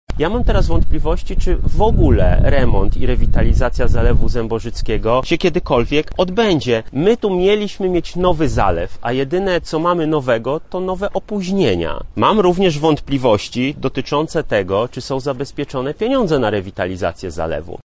• twierdzi poseł Krawczyk.